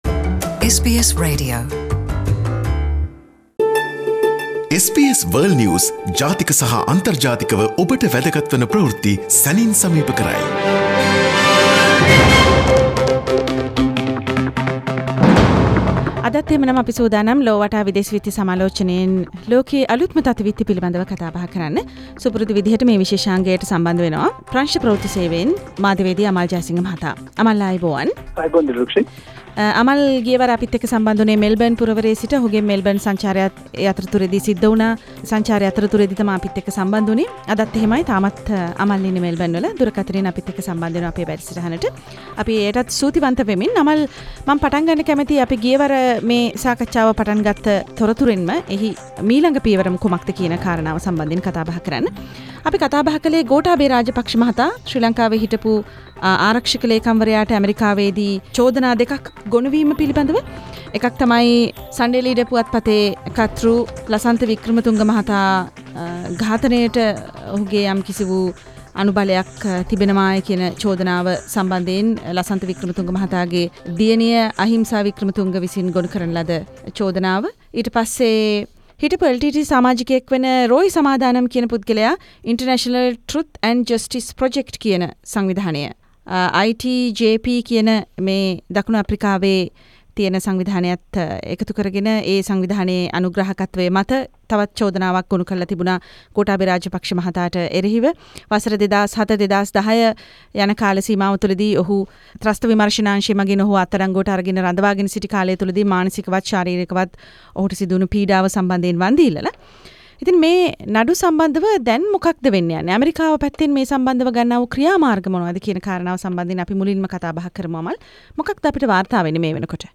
World news wrap Source: SBS Sinhala